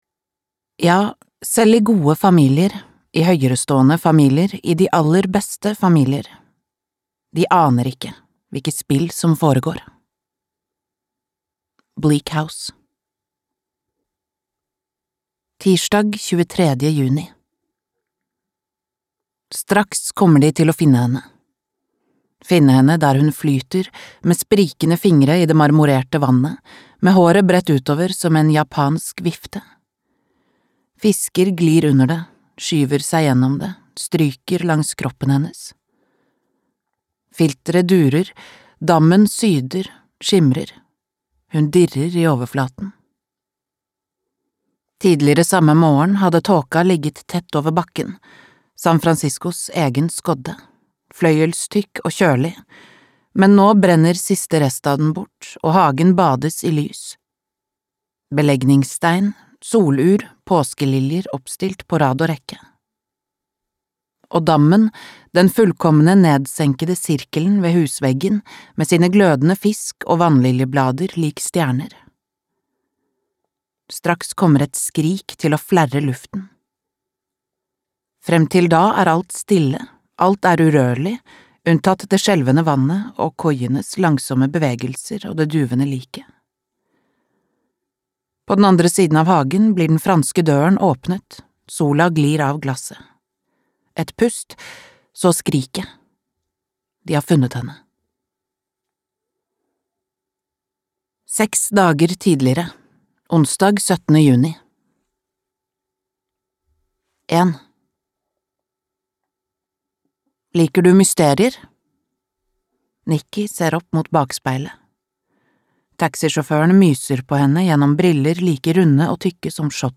Slutten på historien - spenningsroman (lydbok) av A.J. Finn